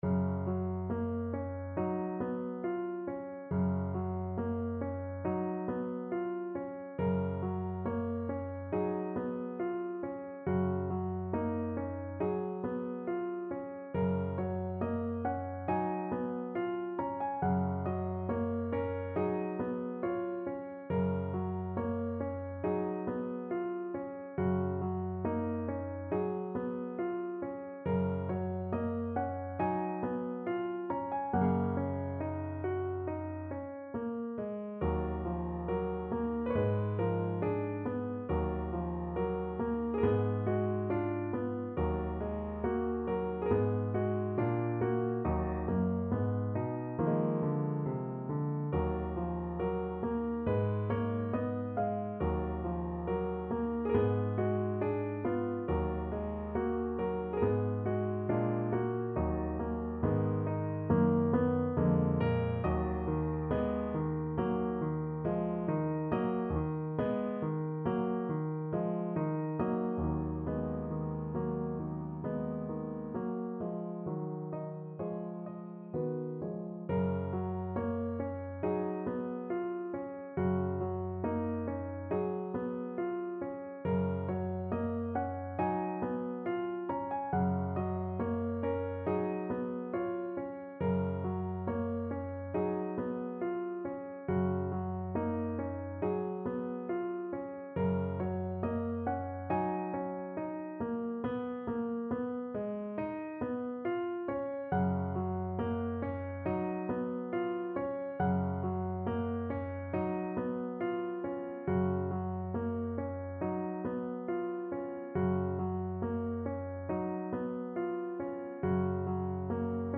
No parts available for this pieces as it is for solo piano.
4/4 (View more 4/4 Music)
Gb major (Sounding Pitch) (View more Gb major Music for Piano )
~ =69 Poco andante
Classical (View more Classical Piano Music)
ilyinsky_op13_7_berceuse.mp3